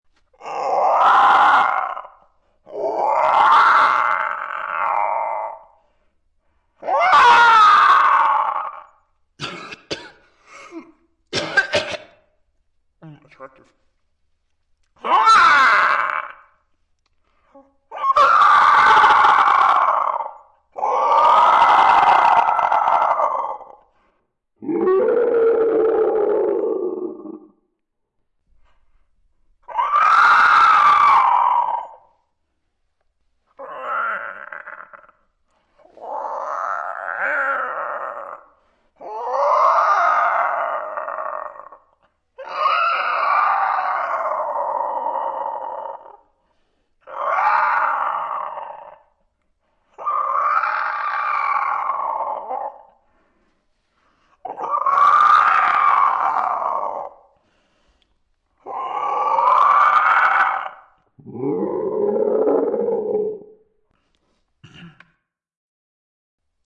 Download Raptor sound effect for free.
Raptor